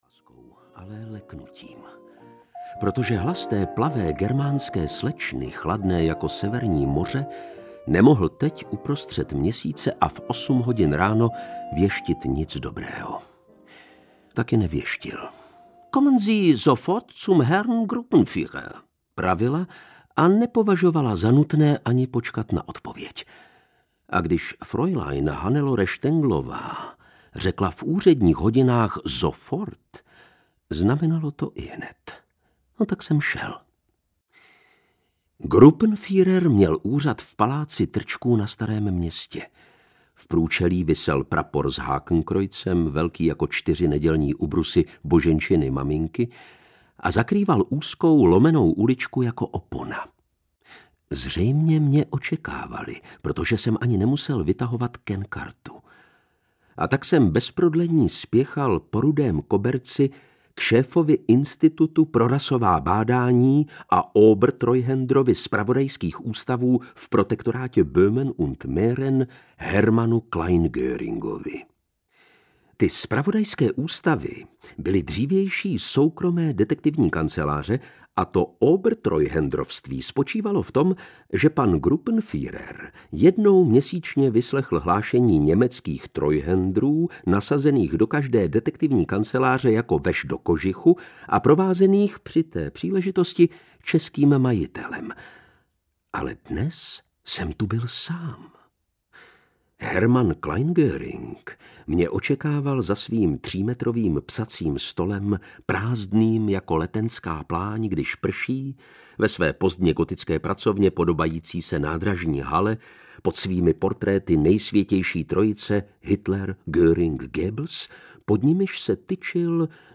Vražda v zastoupení audiokniha
Ukázka z knihy
• InterpretLukáš Hlavica